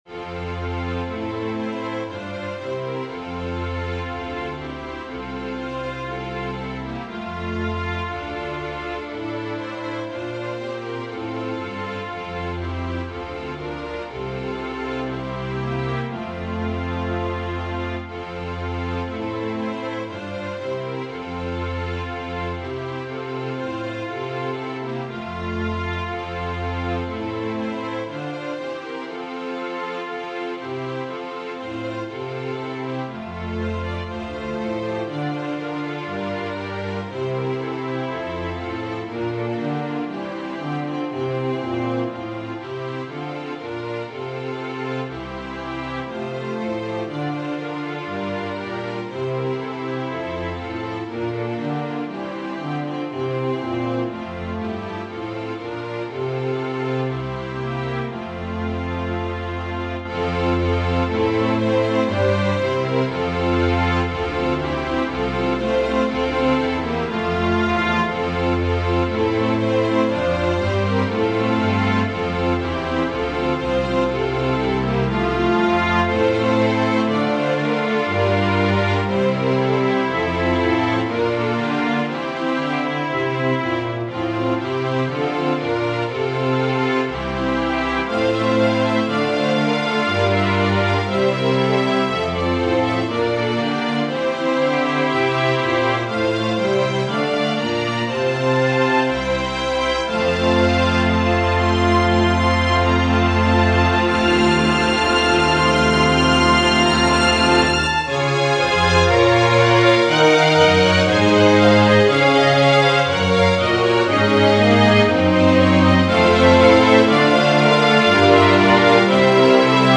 Organ/Organ Accompaniment
Voicing/Instrumentation: Organ/Organ Accompaniment We also have other 56 arrangements of " Angels We Have Heard on High ".